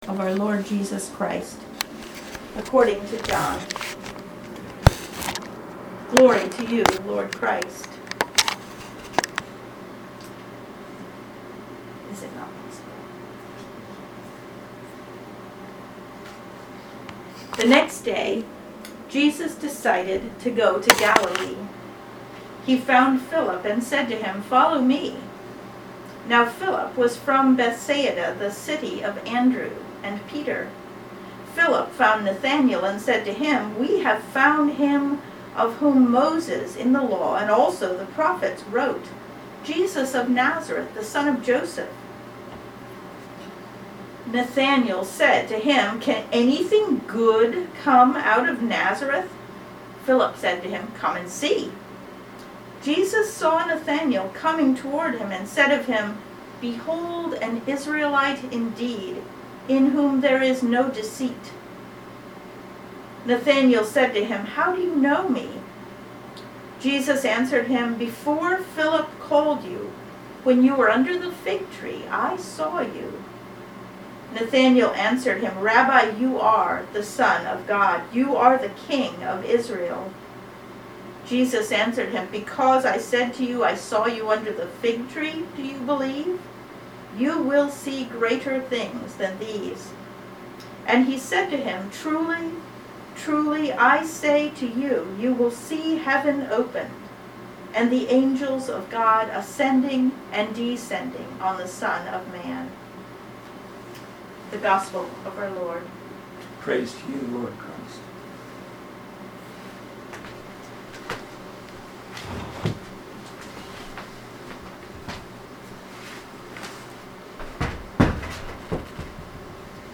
sermon-for-epiphany-2.mp3